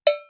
cowbell.wav